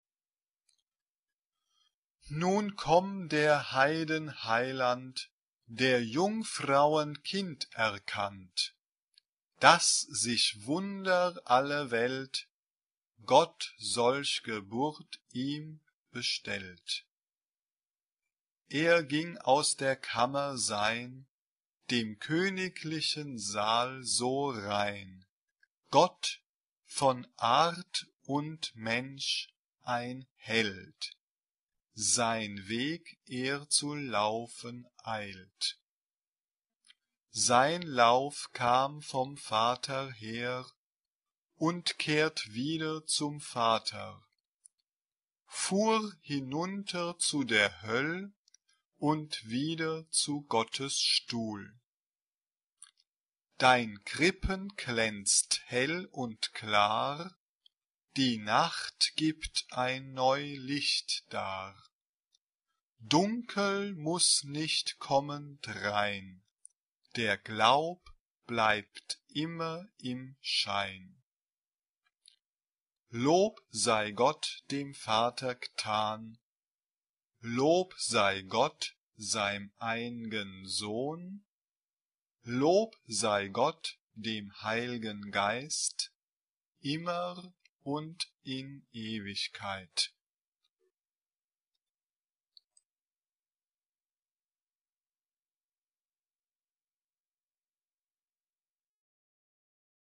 Sagrado ; Coro
SSA (3 voces Coro femenino )
Teclado (1 partes instrumentales)
Tonalidad : sol menor